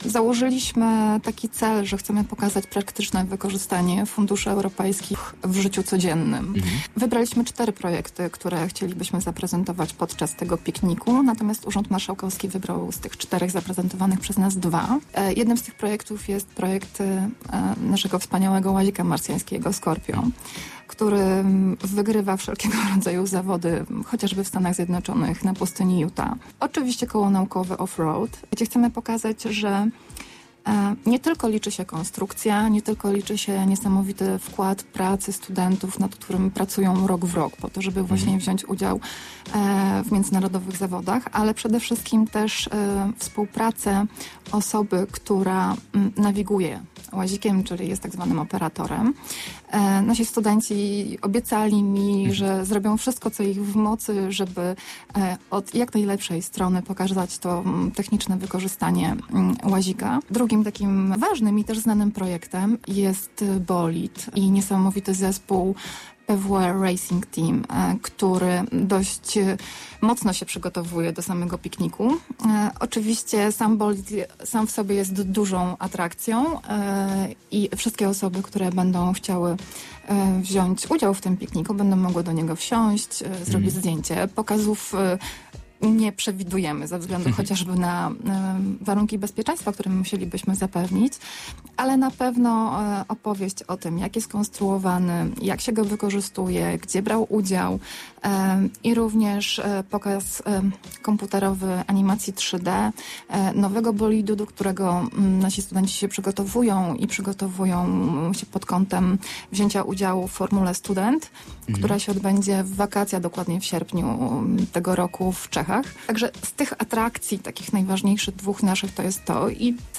Akademickiemu Radiu Luz